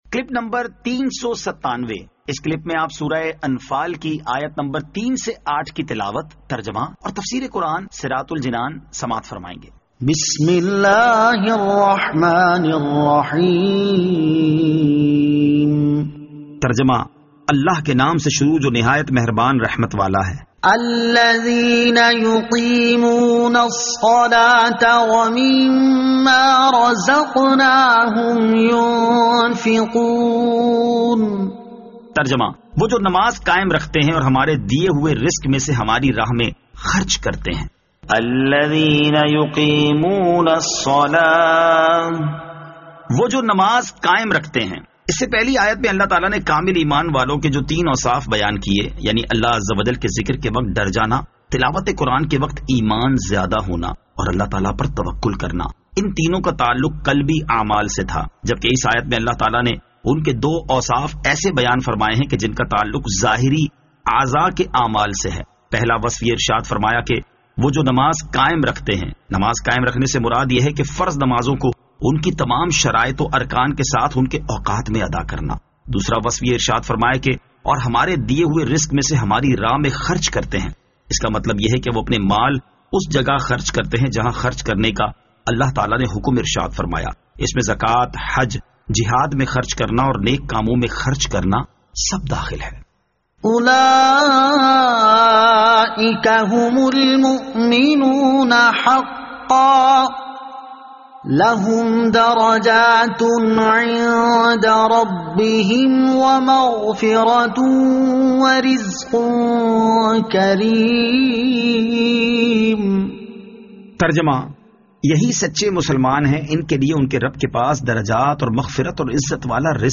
Surah Al-Anfal Ayat 03 To 08 Tilawat , Tarjama , Tafseer